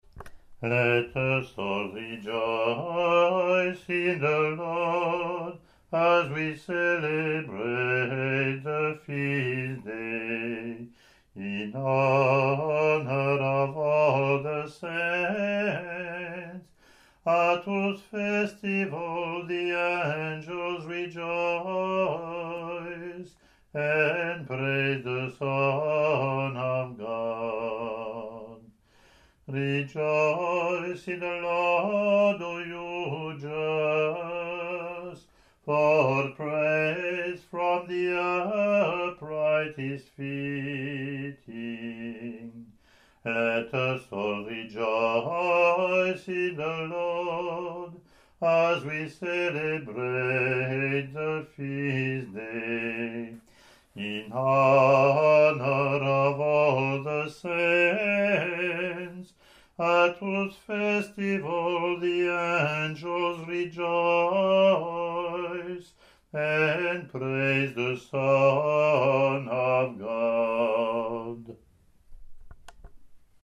English antiphon – English verse